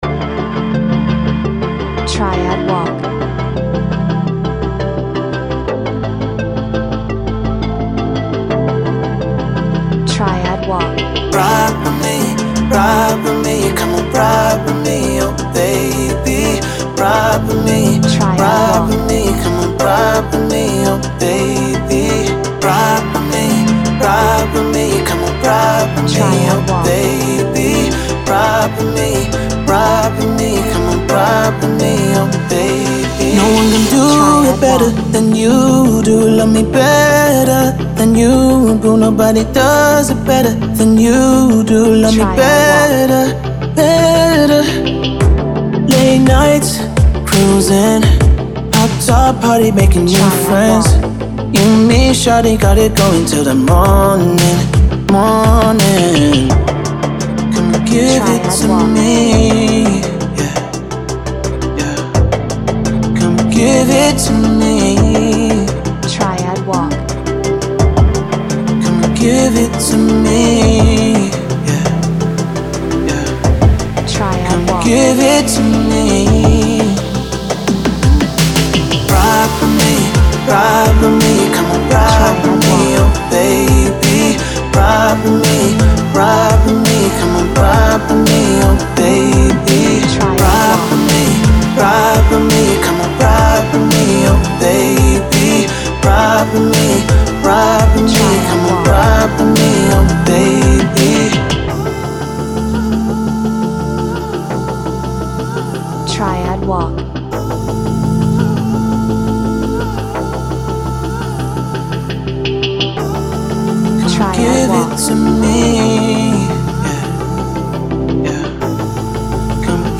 Pop , R&B , Male Vocal
passionate , Bass , Fashionable , Drum
Synthesizer
Romantic